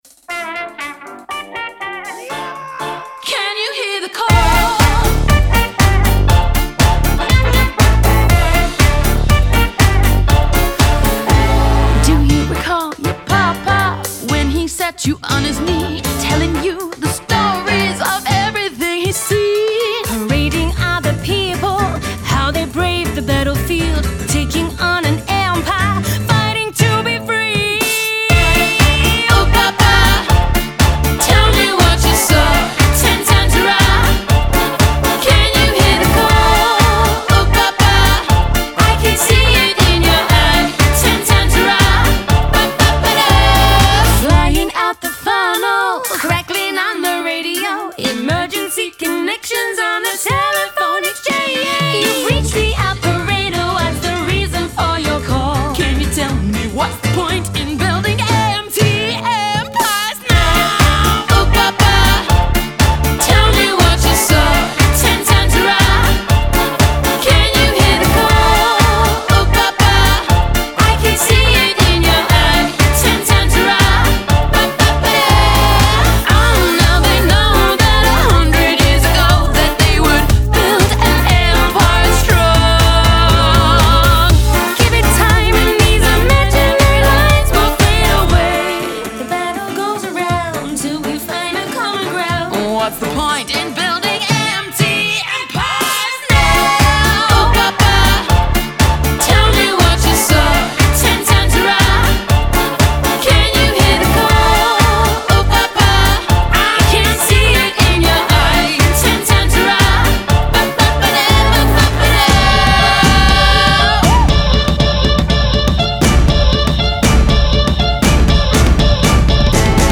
BPM120-120
Audio QualityPerfect (High Quality)
Electro swing song for StepMania, ITGmania, Project Outfox
Full Length Song (not arcade length cut)